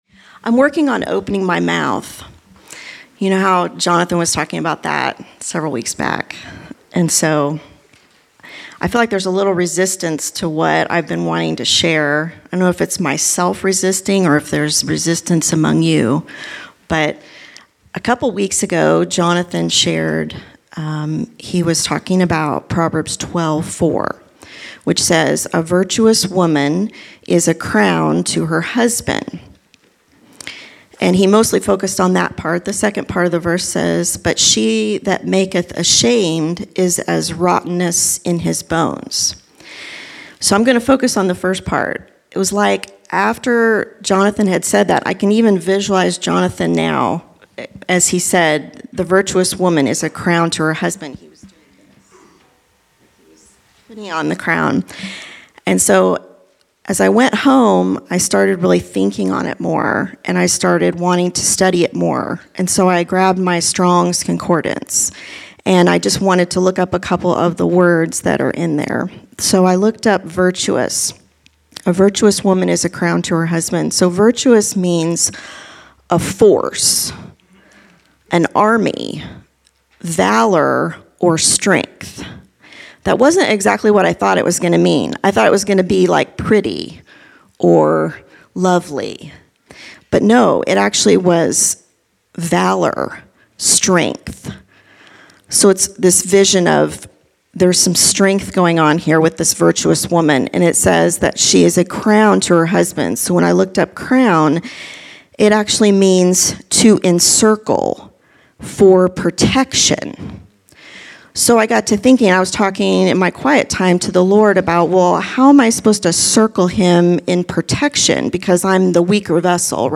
Category: Testimonies      |      Location: El Dorado